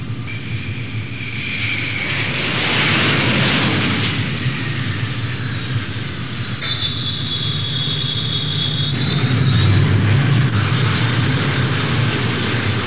دانلود صدای طیاره 35 از ساعد نیوز با لینک مستقیم و کیفیت بالا
جلوه های صوتی